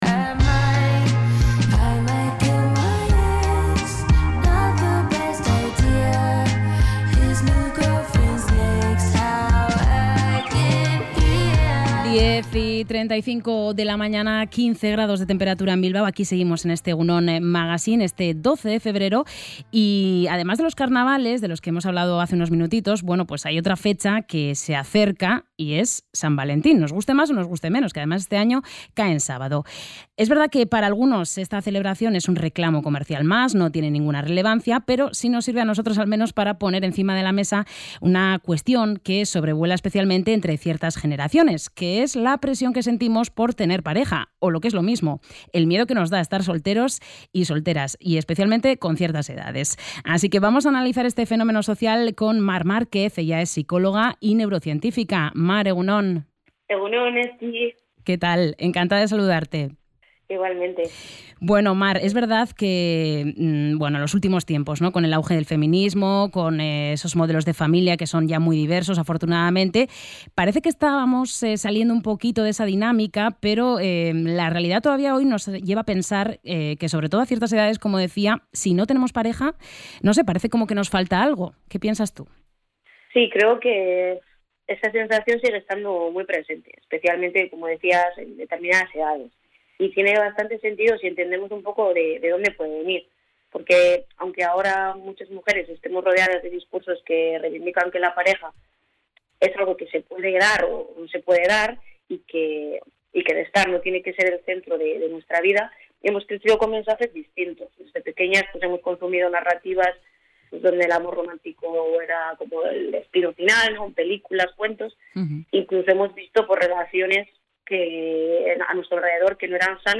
ENTREV.-RELACIONES-DE-PAREJA.mp3